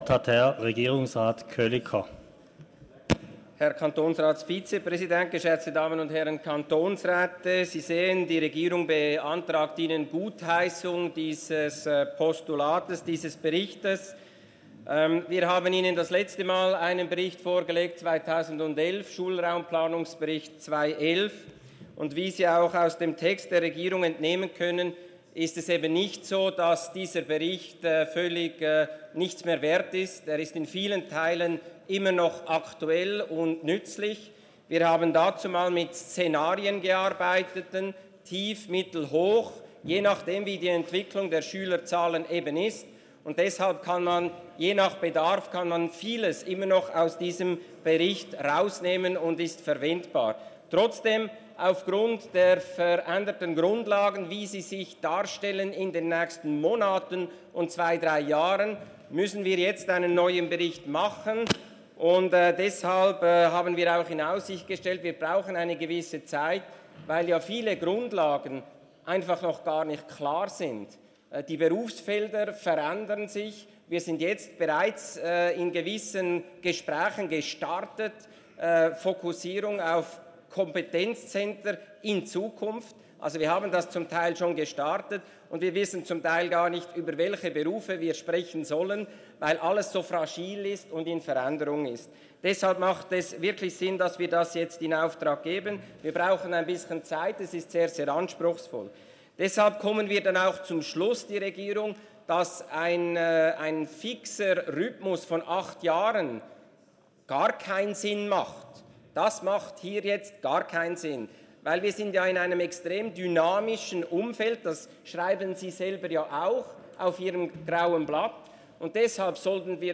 Regierungsrat:
Session des Kantonsrates vom 11. bis 13. Juni 2019